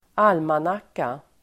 Uttal: [²'al:manak:a]